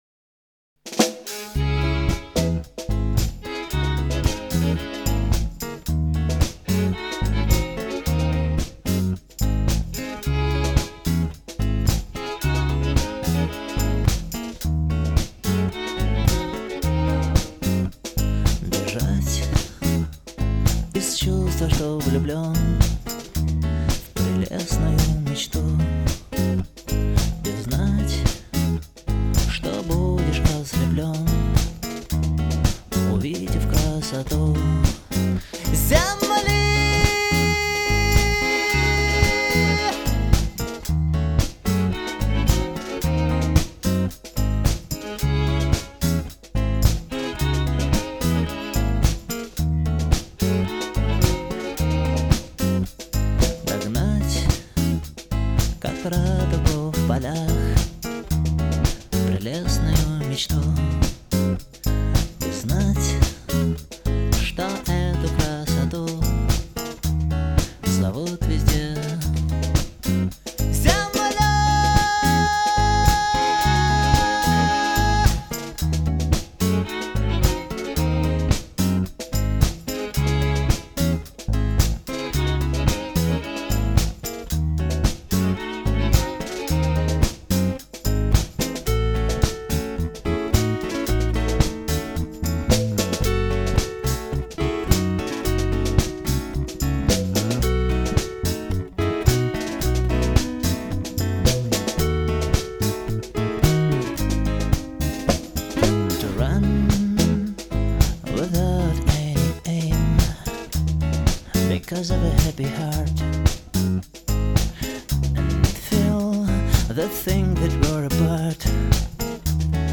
Поп рок